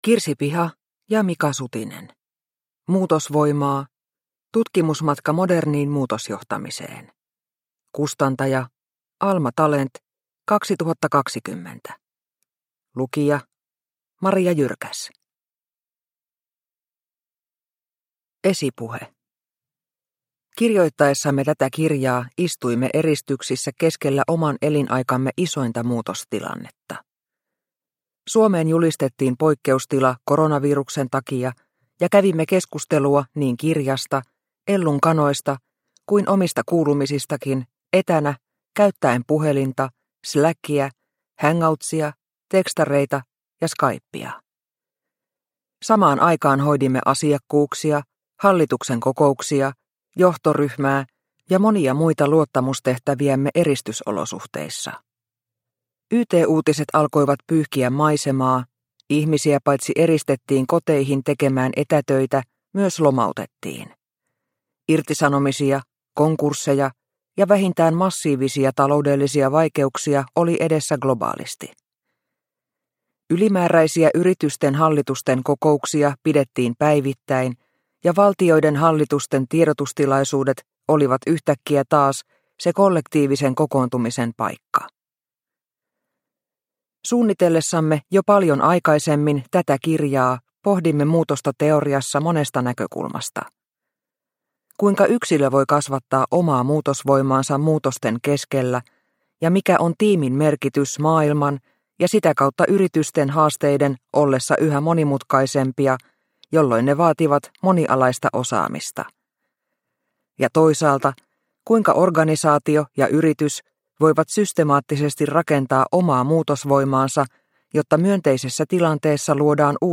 Muutosvoimaa – Ljudbok – Laddas ner